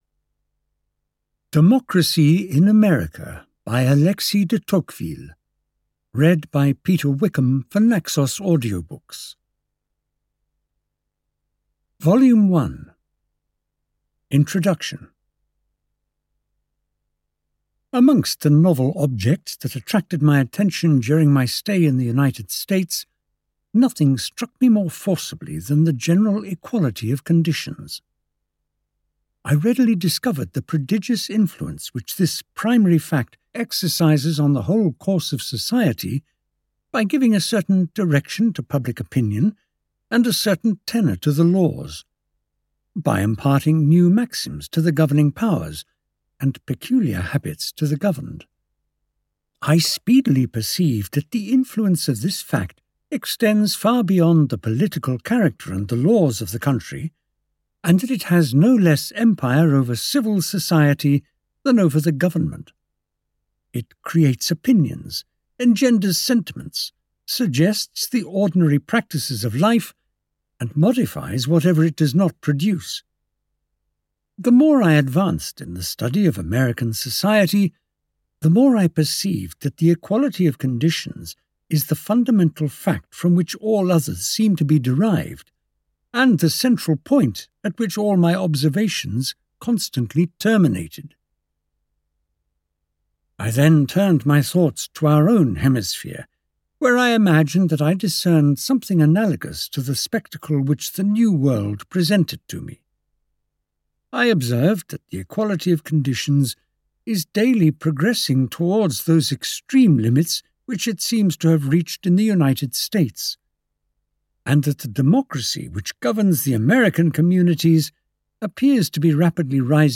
Democracy in America (EN) audiokniha
Ukázka z knihy